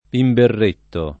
imberretto [ imberr % tto ]